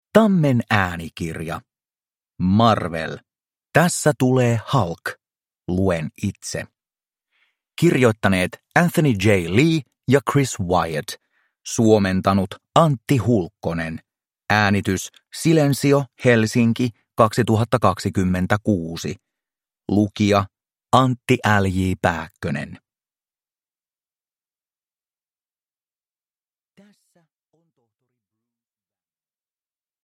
Marvel. Tässä tulee Hulk. Luen itse. – Ljudbok